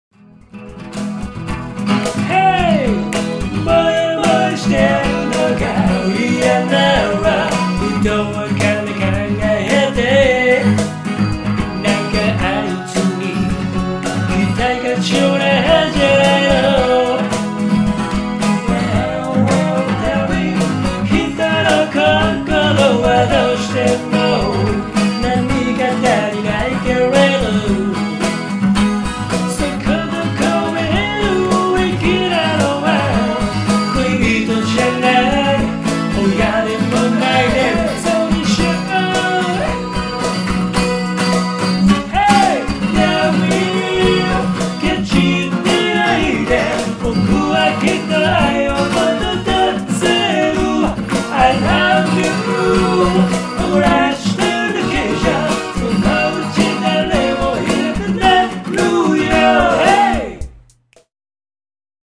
過去に、ほんの少し（１分くらい）カバーした曲です。
カッチリコピーではありませんので、軽い気持ちで聴いてみてください。